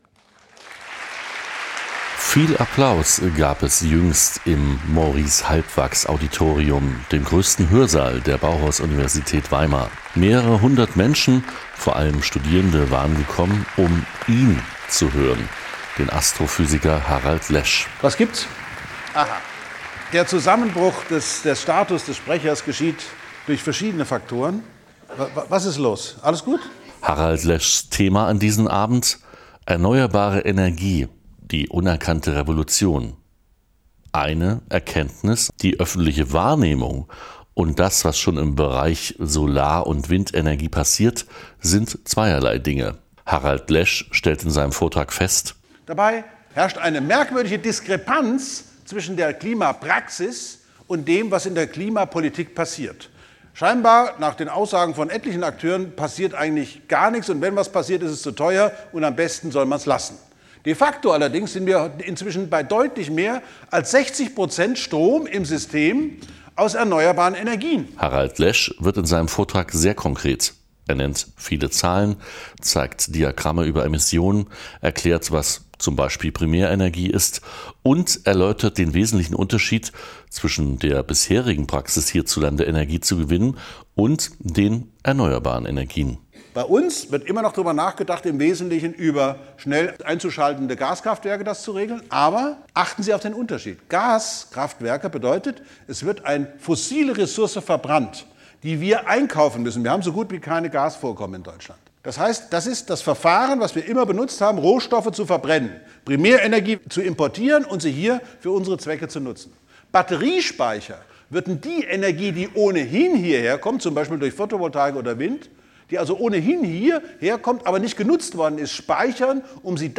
In einer Veranstaltung im Audimax der Bauhaus-Uni ging es daher jüngst um den Stand der Dinge bei den erneuerbaren Energien.